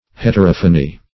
Search Result for " heterophony" : The Collaborative International Dictionary of English v.0.48: Heterophony \Het`er*oph"o*ny\, n. [Hetero- + Gr.